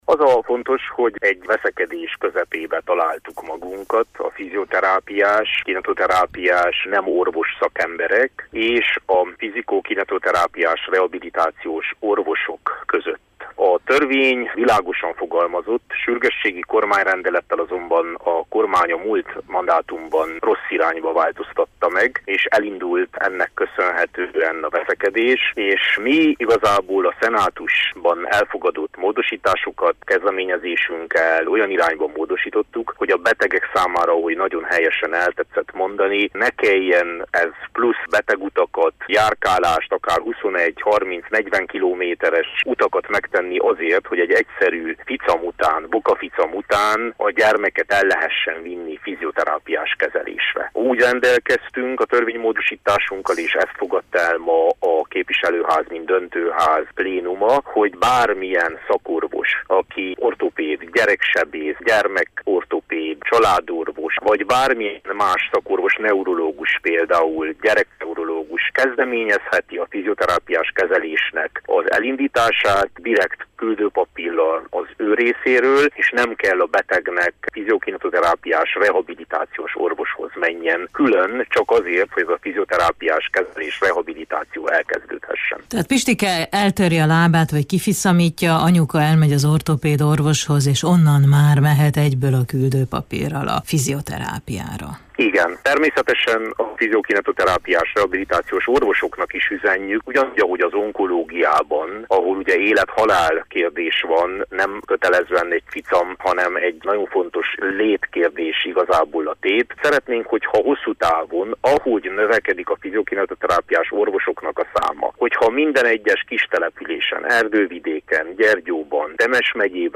Dr. Vass Levente, parlamenti képviselőt, egészségügyi szakpolitikst kérdezte